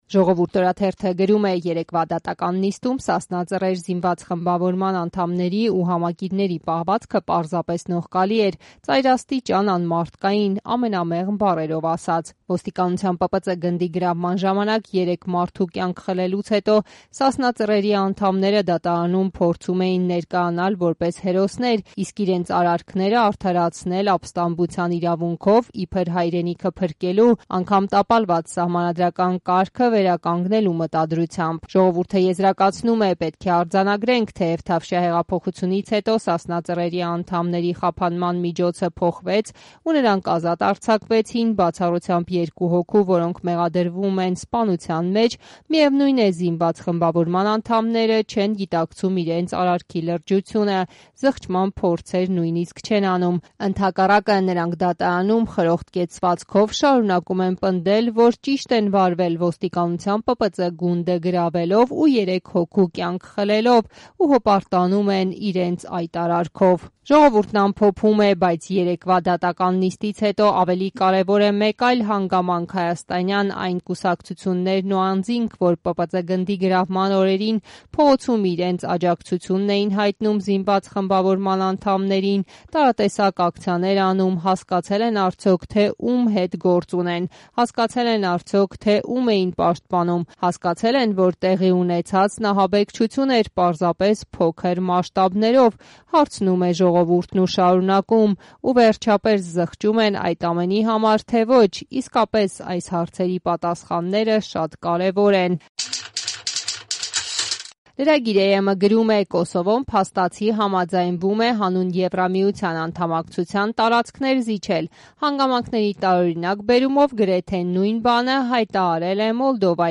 Մամուլի տեսություն